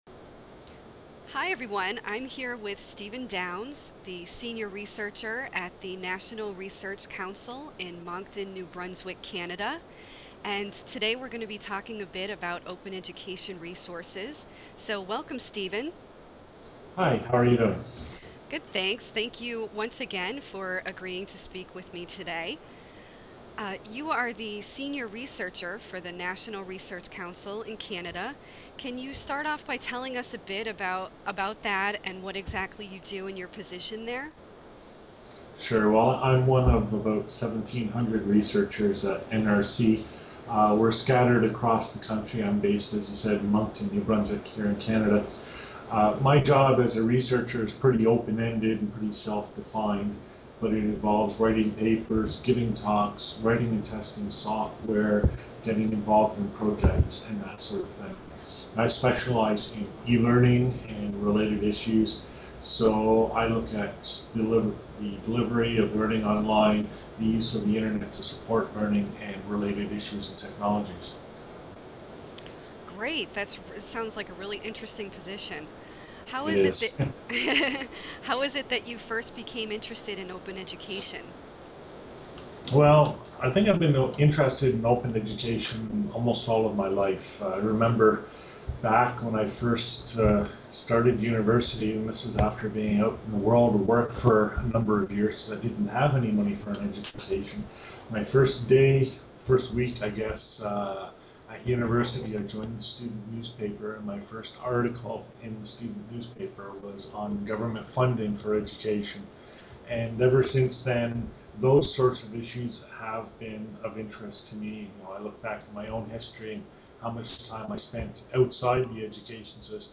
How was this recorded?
(Old style) [ Audio ] (New Style) [] , Skype, Interview, Sept 14, 2008.